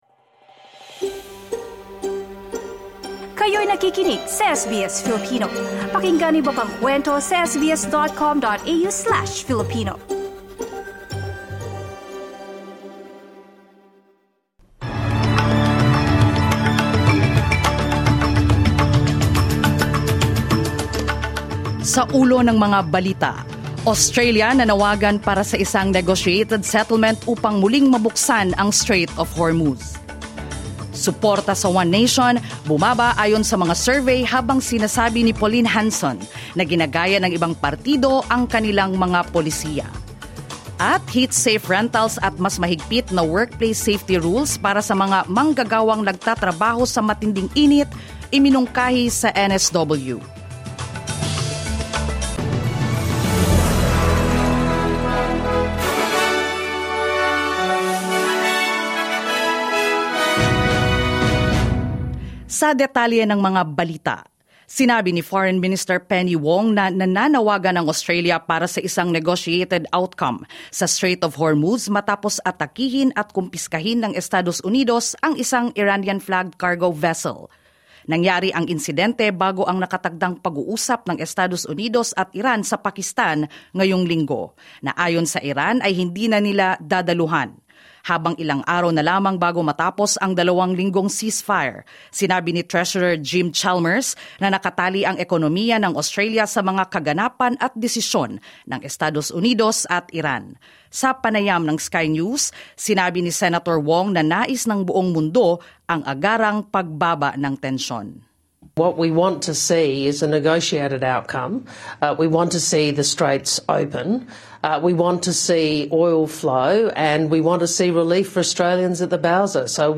SBS News in Filipino, Tuesday 21 April 2026